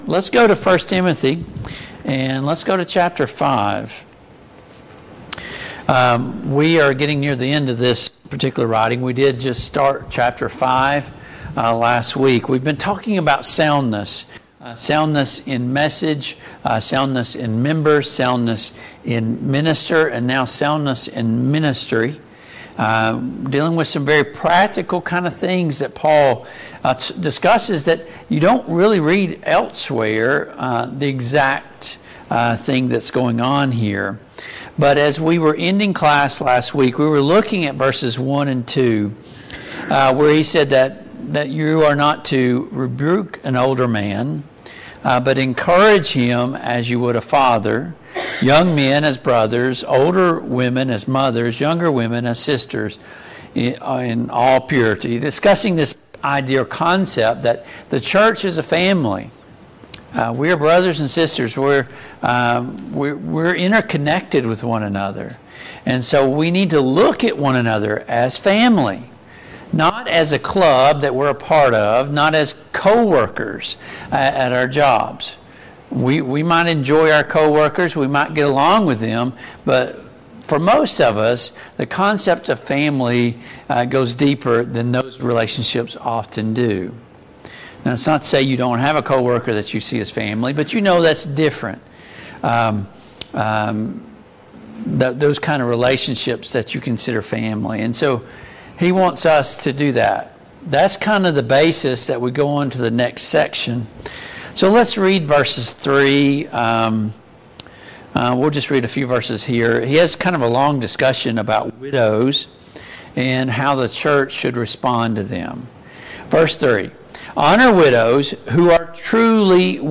Passage: 1 Timothy 5:1-25 Service Type: Mid-Week Bible Study Download Files Notes « 23.